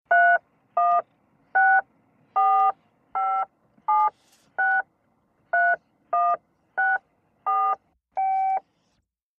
Cell phone dialing 12 digits, send